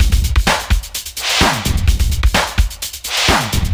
VMH1 Minimal Beats 14.wav